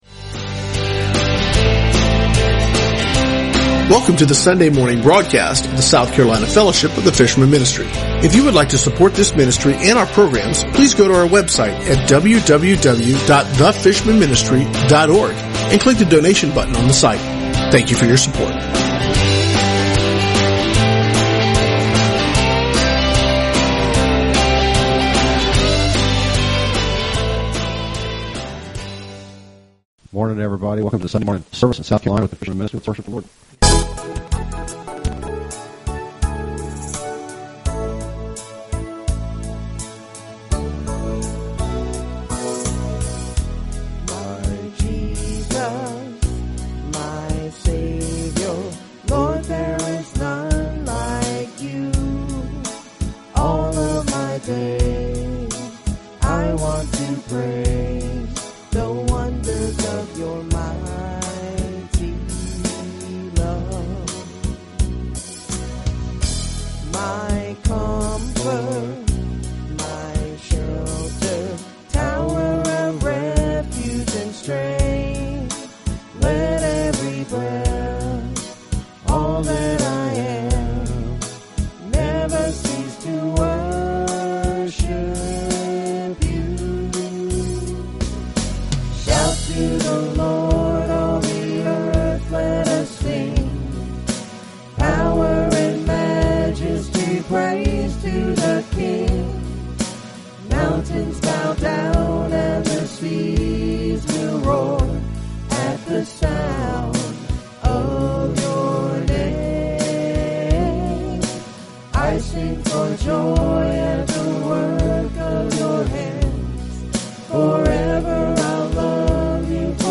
Sunday South Carolina Church Service 03/20/2016 | The Fishermen Ministry